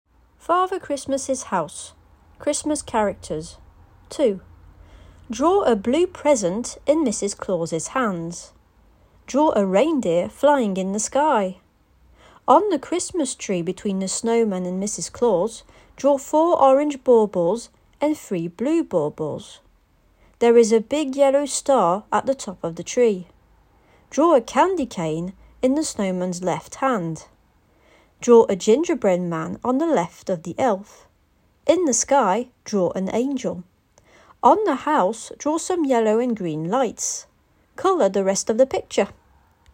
Des fichiers audio avec une voix anglaise native accompagnent l'ensemble conçu prioritairement pour le Cycle 3, mais avec des adaptations possibles pour le Cycle 2.